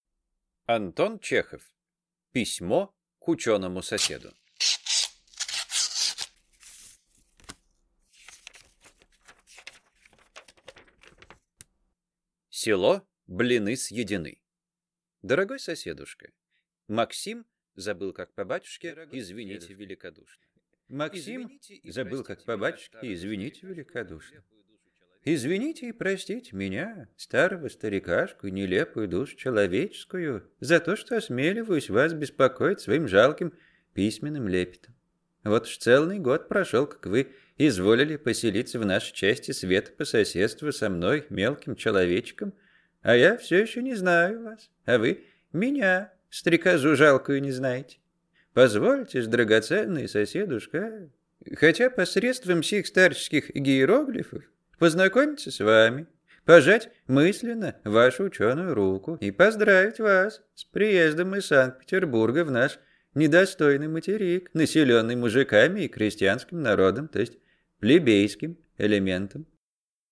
Аудиокнига Письмо к ученому соседу | Библиотека аудиокниг